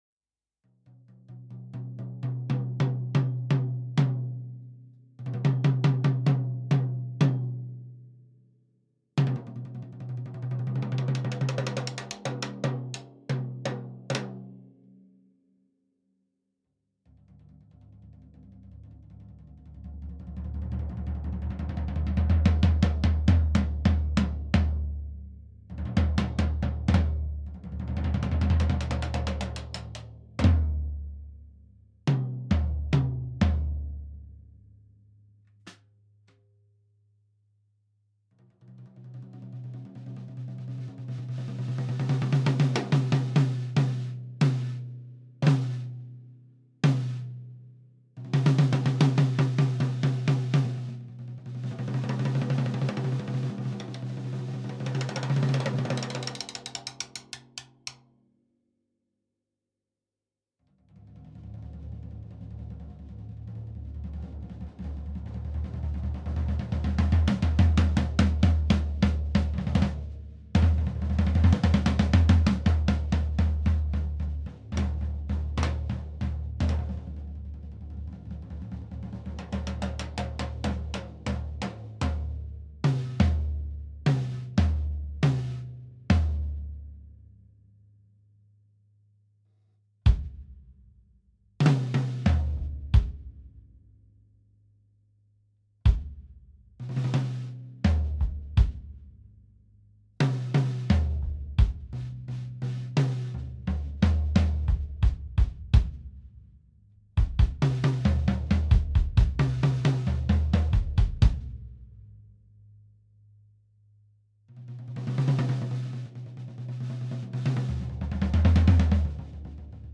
Mukavan herkkä, ei tartte paiskoa.
Äänittelin pienen testipätkän Sakae-setistä, yritin demota esim. tarjolla olevaa dynamiikkaa:
Virvelinä oli Saari-koivuvirveli kooltaan 14x5,5".